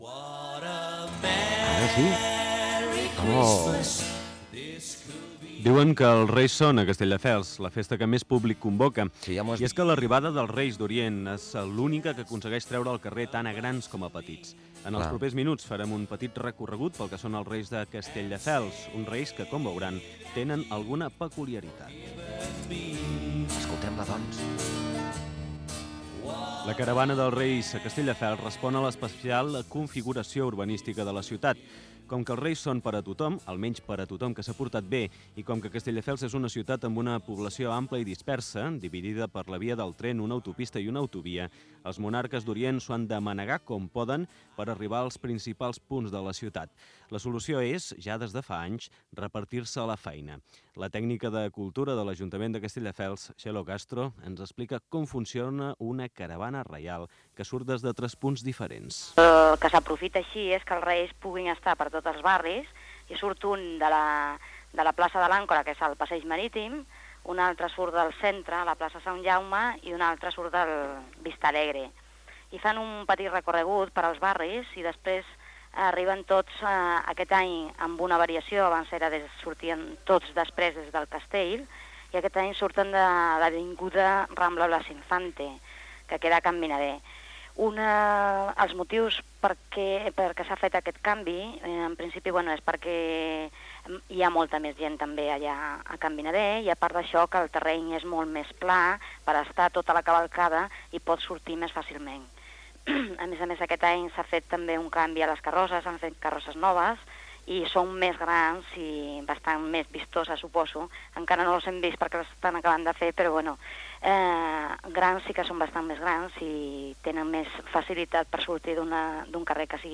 Presentació i crònica de l'arribada dels Reis d'Orient a Castelldefels
Entreteniment